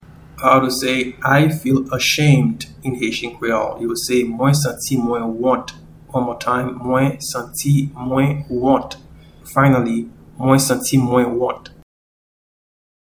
Pronunciation and Transcript:
I-need-a-urine-sample-in-Haitian-Creole-Mwen-bezwen-yon-echantiyon-pise.mp3